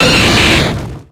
Cri de Magmar dans Pokémon X et Y.